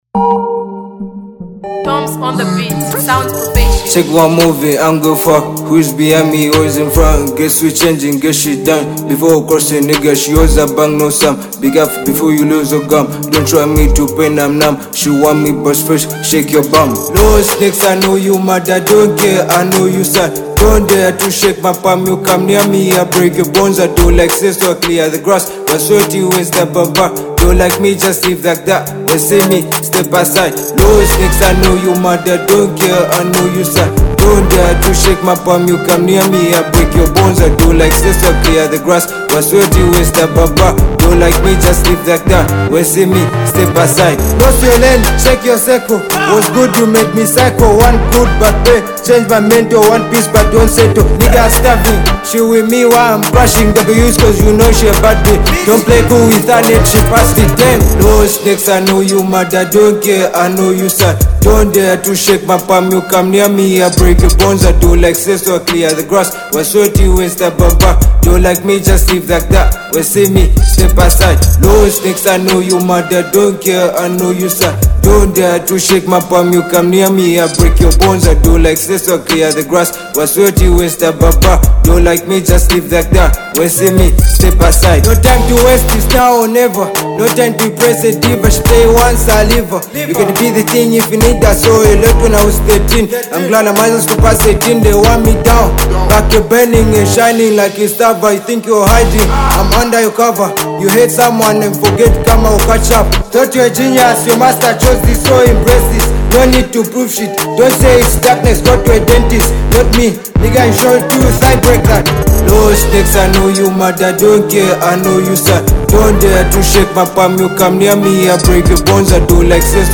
head-bopping sound